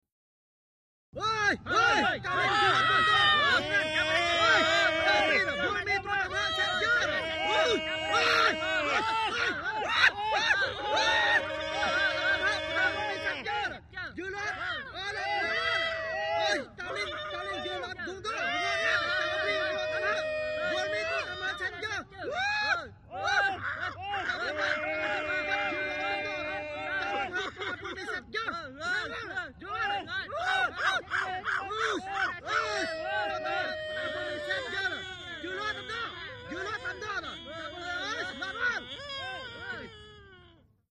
Troop Activity: ( Foreign ) Vietnamese Male Group Yells And Vocal Noises. Excited Or Upset. Birds And Planes In Distant Background.